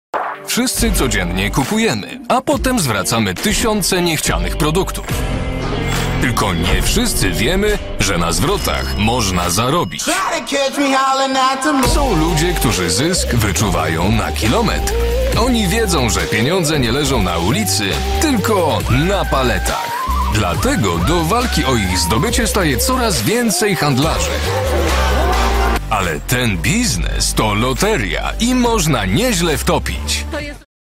Mężczyzna 30-50 lat
Lektor szeptankowy - głos Netflixa, HBO, Disney+, National Geographic i wielu innych kanałów telewizyjnych.